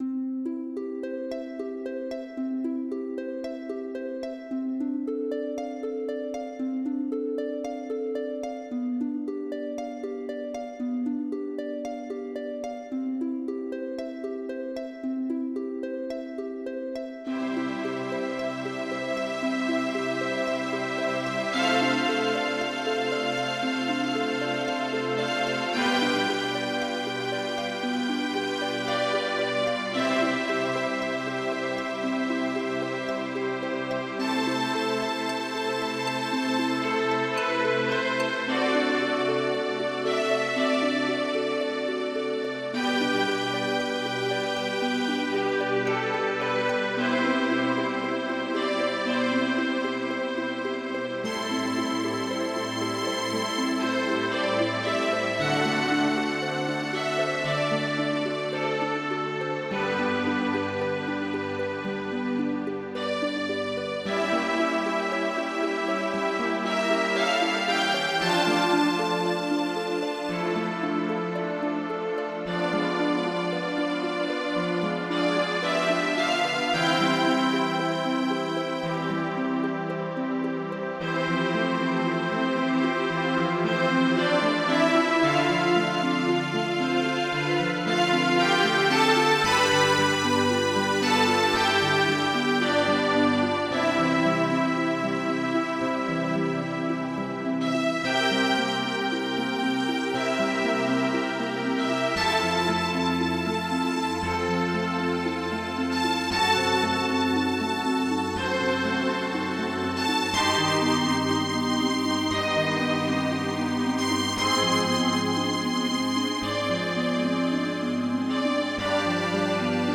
MIDI Music File
AVE MARIA Type General MIDI